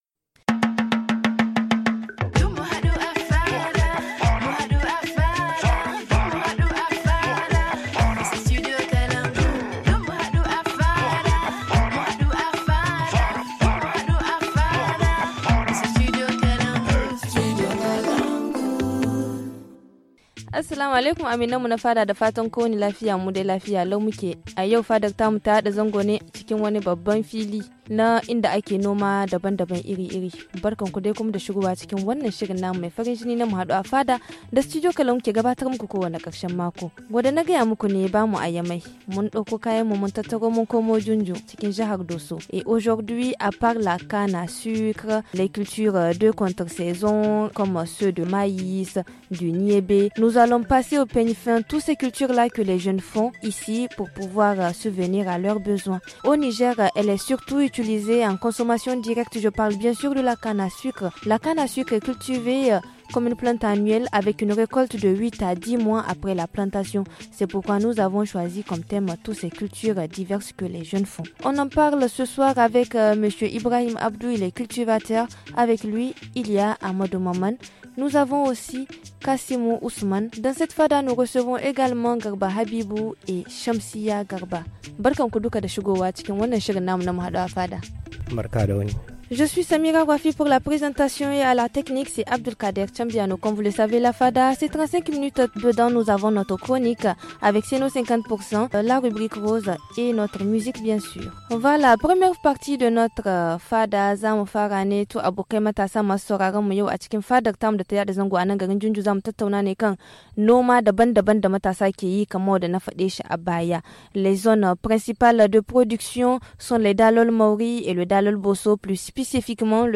Ce samedi, la Fada s’est délocalisée de Niamey la capitale, au département de Dioundiou dans la région de Dosso. Nous nous intéressons à la culture de la canne à sucre, du maïs et d’autres céréales dans cette localité.